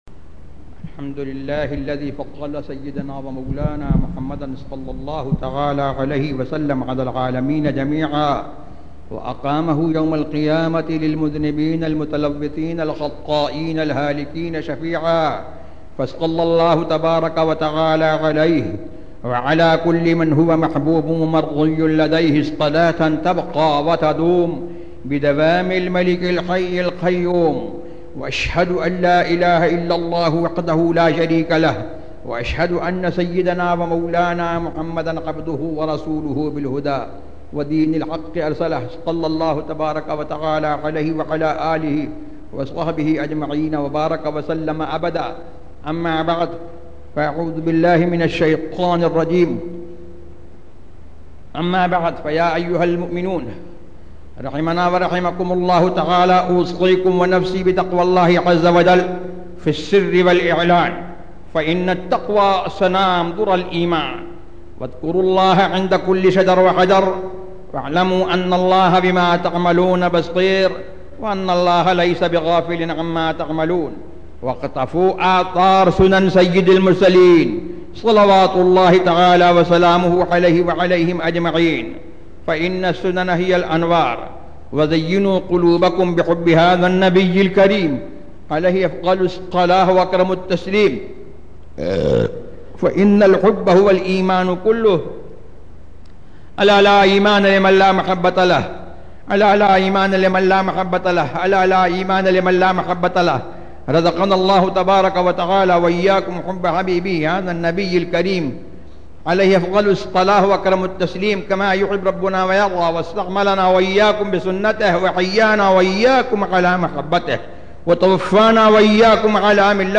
Khutba F.B.Area
خطبہ
khutba-f-b-area.mp3